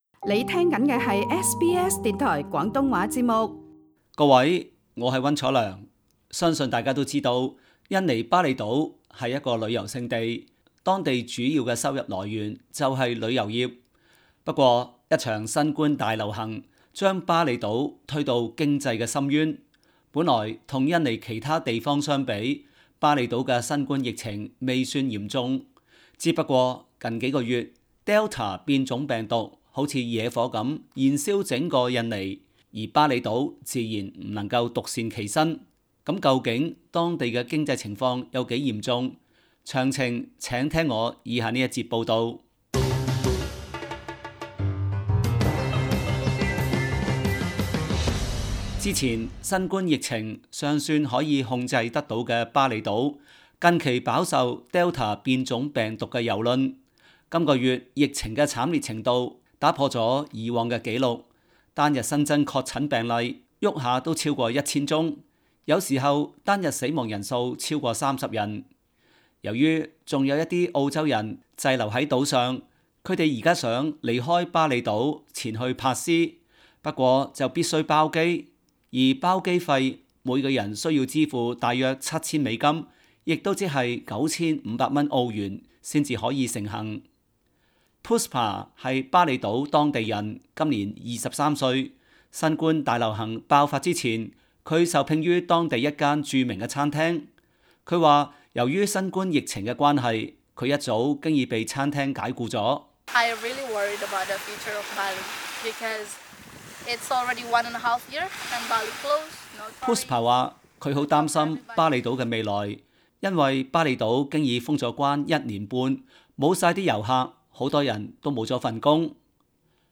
Source: SBS SBS廣東話節目 View Podcast Series Follow and Subscribe Apple Podcasts YouTube Spotify Download (5.43MB) Download the SBS Audio app Available on iOS and Android 一場新冠大流行，將峇厘島 (Bali) 推向經濟懸崖。